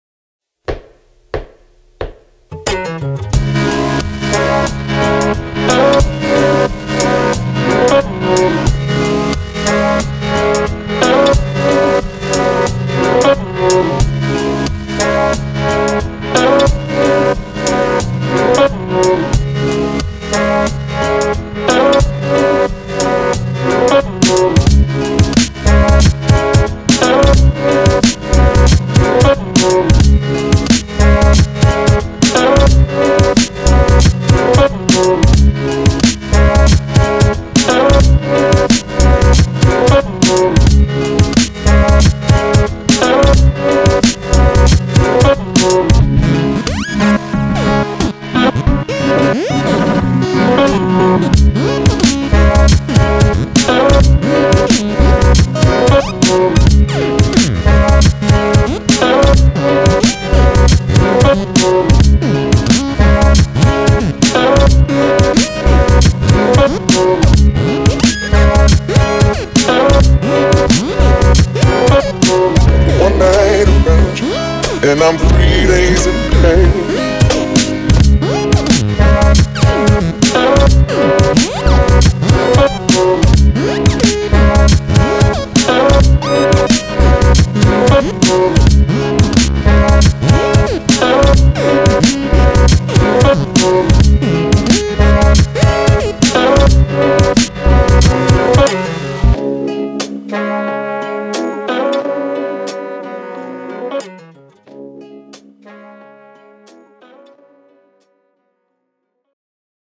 Style : Electronic, Hip Hop, Jazz